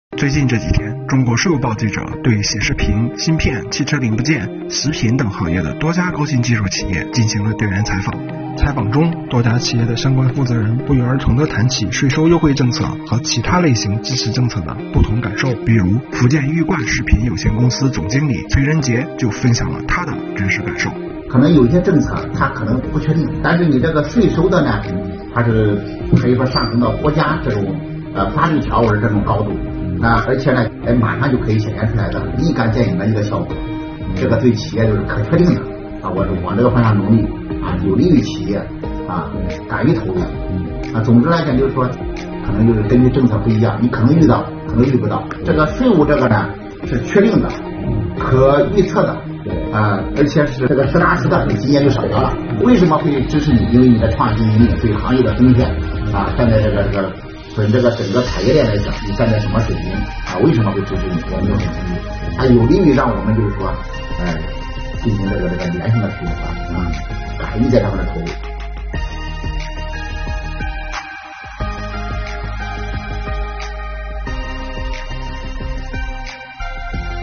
最近这几天，中国税务报记者对显示屏、芯片、汽车零部件、食品等行业的多家高新技术企业进行了调研采访。